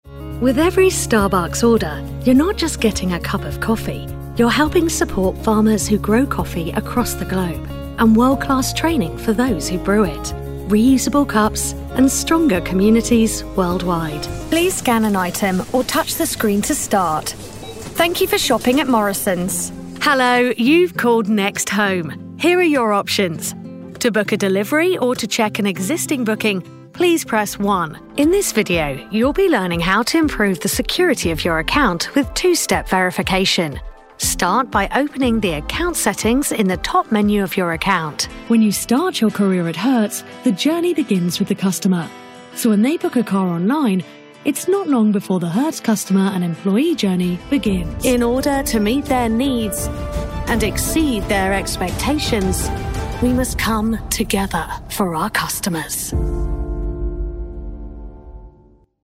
Englisch (Britisch)
Natürlich, Zugänglich, Vielseitig, Erwachsene, Sanft
Unternehmensvideo